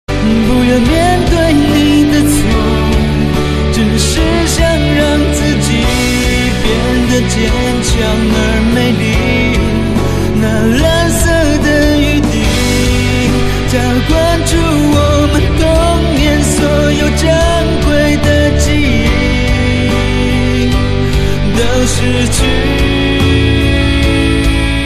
M4R铃声, MP3铃声, 华语歌曲 73 首发日期：2018-05-14 21:38 星期一